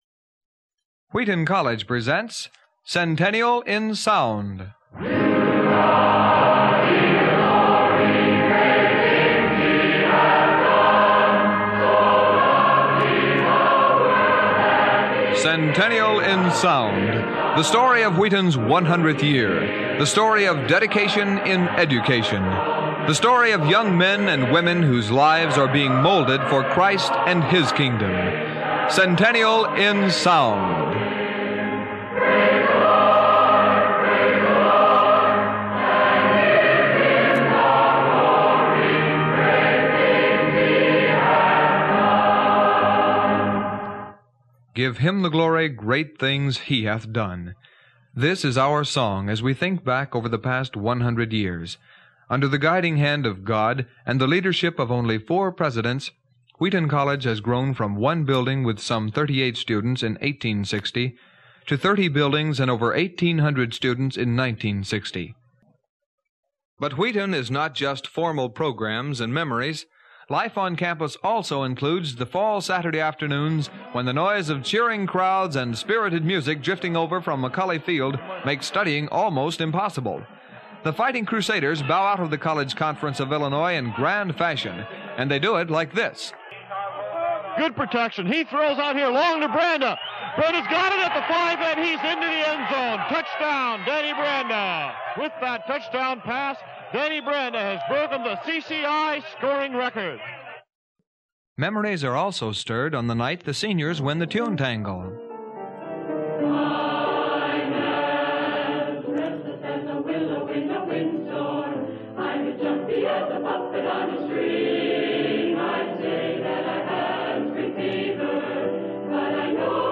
When I was a Junior at Wheaton College, for special credit, I agreed to record every single Centennial event and produce an audio yearbook – ‘Centennial in Sound.’ Under the auspices of WETN (before FM), I traveled all over campus and Chicagoland with my faithful Magnecorder reel-to-reel tape recorder, along with a microphone and mike stand, plus yards of microphone and power cables and boxes of tape.
I edited all that down (with razor blades cutting out pieces of tape) to fit on two sides of a long-play record.